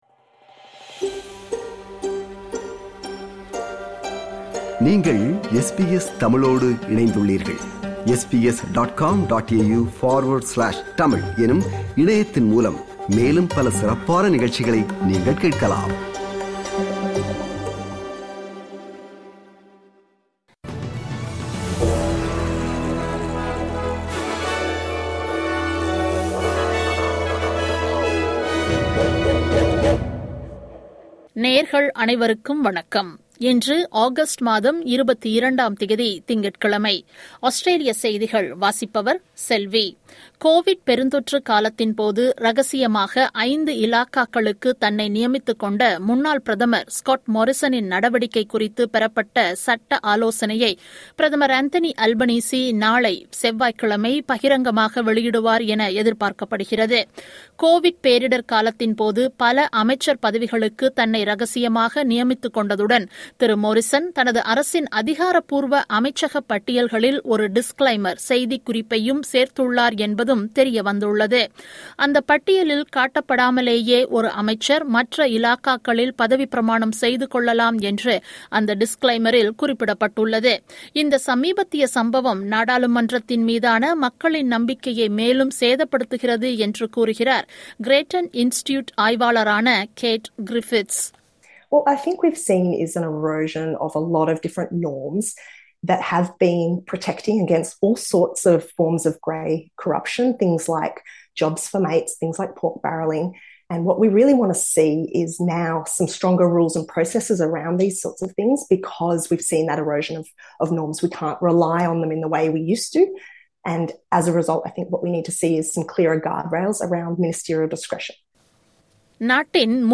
Australian news bulletin for Monday 22 Aug 2022.